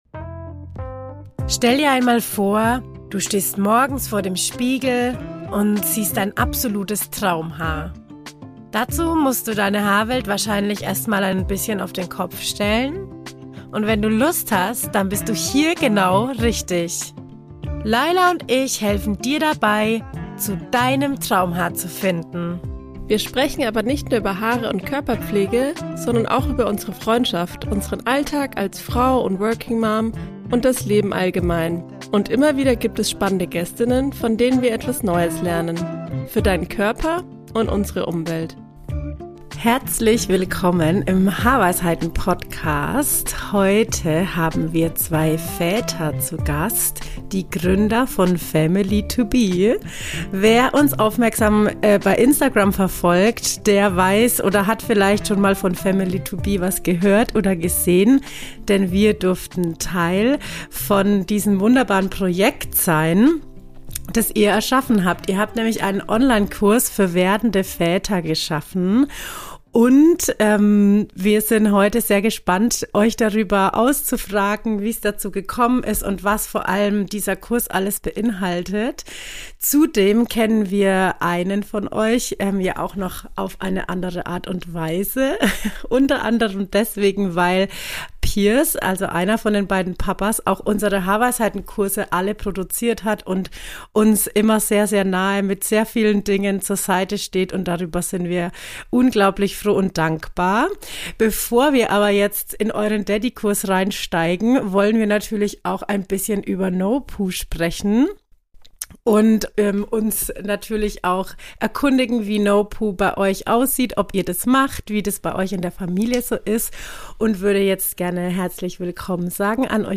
Haarpflege, Vatersein und Family2be: Ein Gespräch